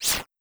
Whoosh & Slash
Slash5.wav